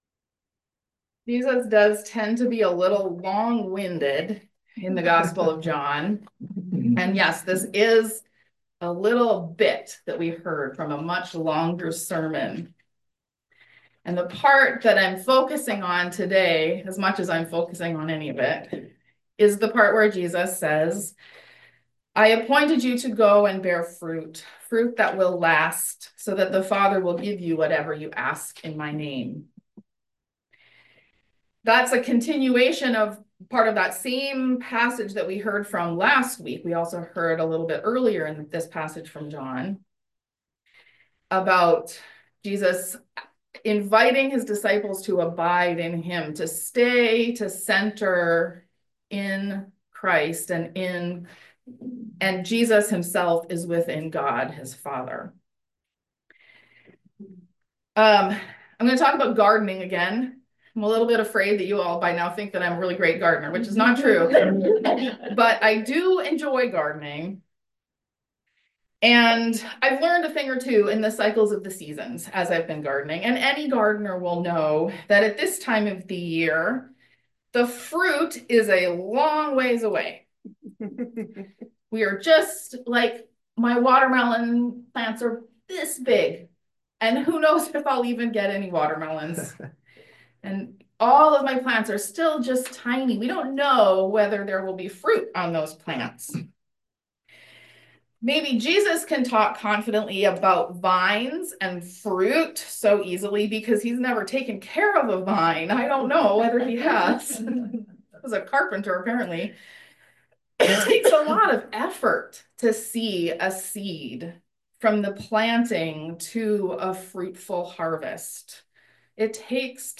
In my sermon today I’m going to talk a little bit about both what some of that effort looks like – that care and tending mentioned – and about how we know when the fruit is ripe.